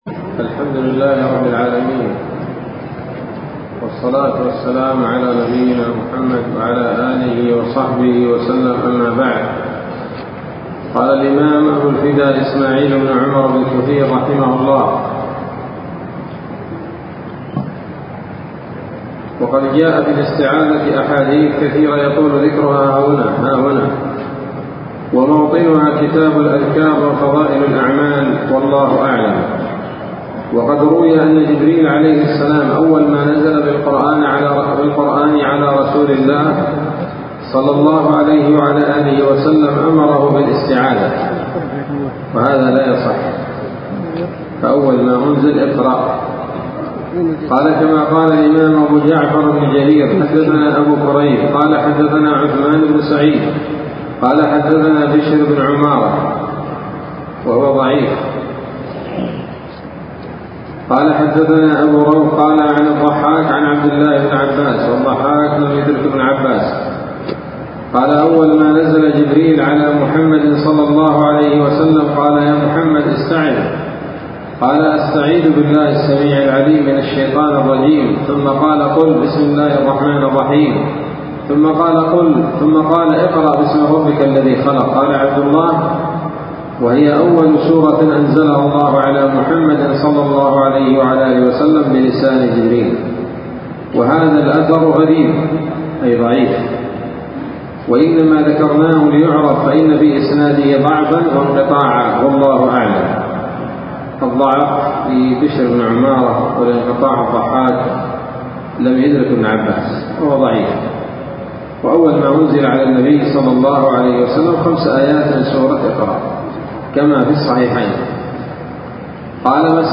الدرس السابع من سورة الفاتحة من تفسير ابن كثير رحمه الله تعالى